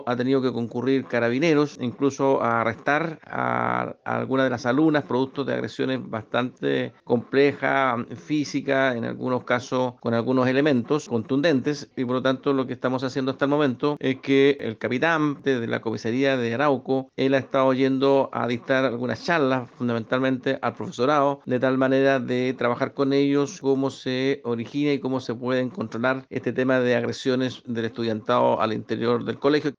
Por su parte, el delegado presidencial de la provincia de Arauco, Humberto Toro, señaló que los últimos incidentes han sido protagonizados por mujeres.